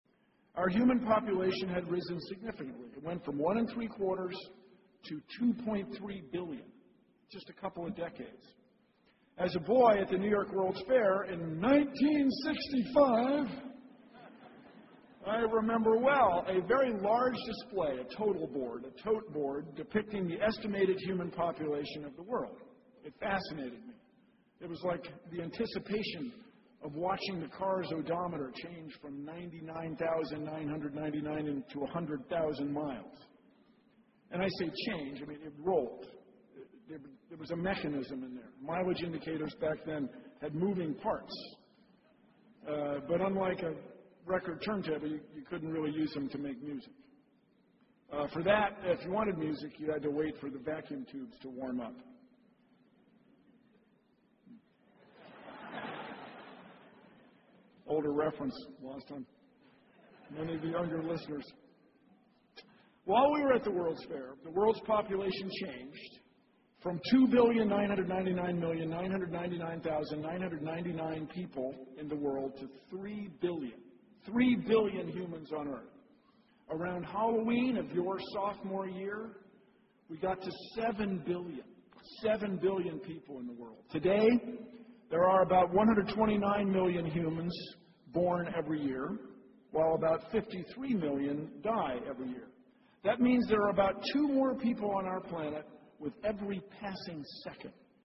在线英语听力室公众人物毕业演讲 第158期:比尔·奈马萨诸塞大学2014(5)的听力文件下载,《公众人物毕业演讲》精选中西方公众人物的英语演讲视频音频，奥巴马、克林顿、金庸、推特CEO等公众人物现身毕业演讲专区,与你畅谈人生。